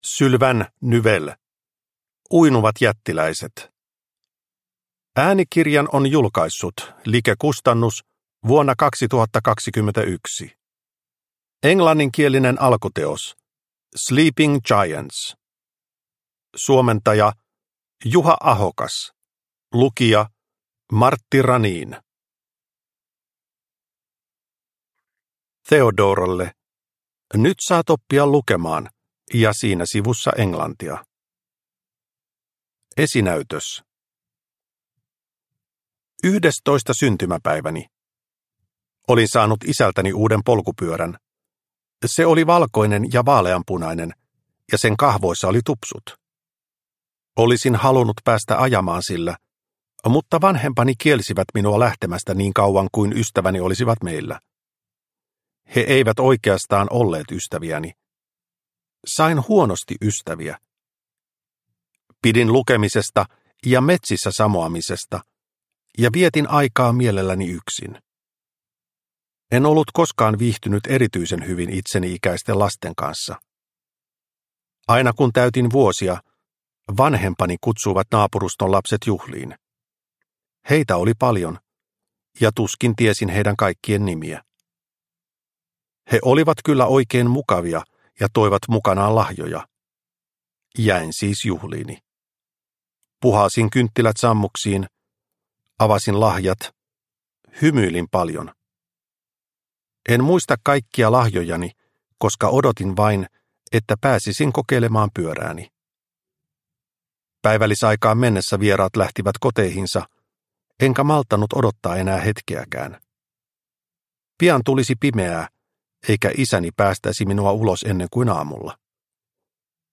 Uinuvat jättiläiset – Ljudbok – Laddas ner